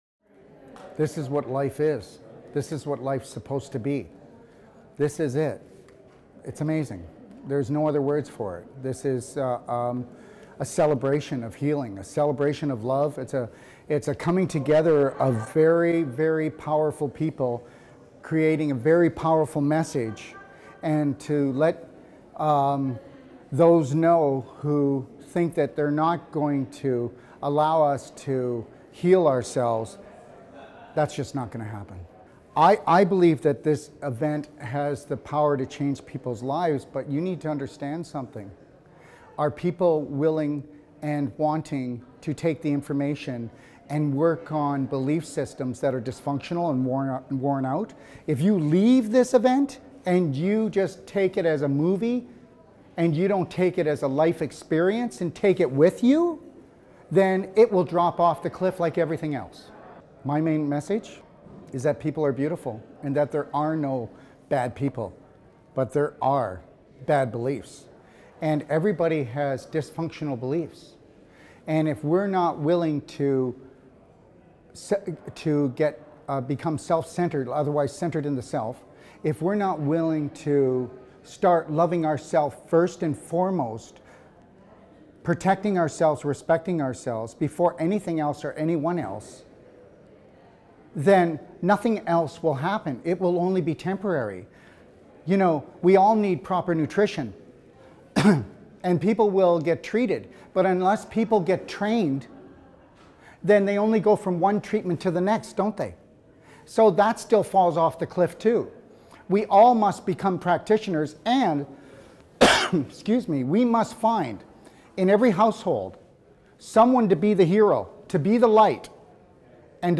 The Truth About Cancer Ultimate Live Symposium